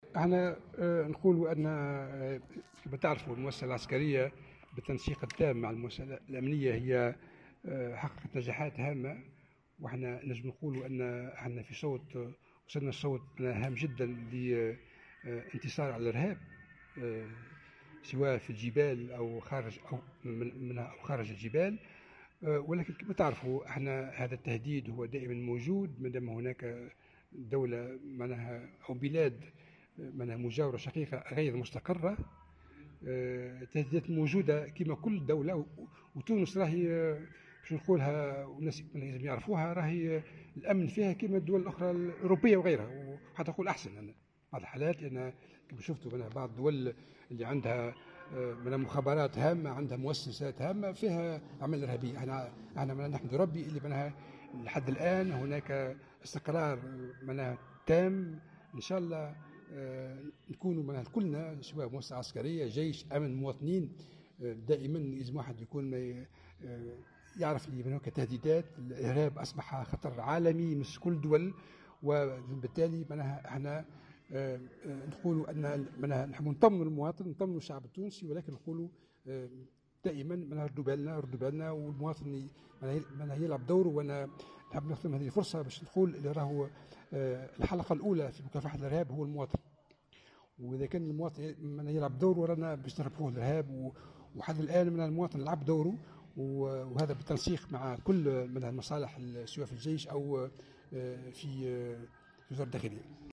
وأضاف الحرشاني في تصريح لمراسل "الجوهرة أف ام" على هامش اختتام الدورة الاستثائية الثانية الخاصة برؤساء التحرير وسائل الإعلام الوطنية المنعقدة من 31 ماي إلى 9 جوان 2017 أن التهديدات الإرهابية ما زالت قائمة، خاصة في ظل عدم استقرار الأوضاع في ليبيا، وهو ما يدعو إلى مزيد الحذر واليقظة.